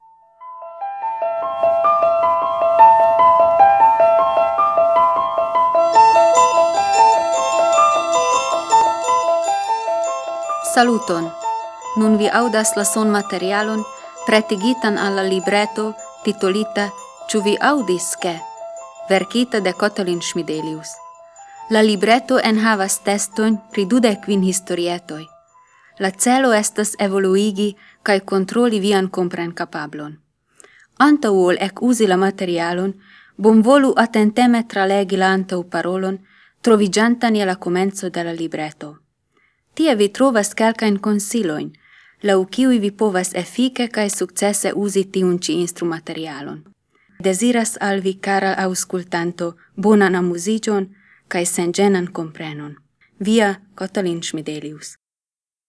Cu-vi-_-mallonga_antauxparolo.wav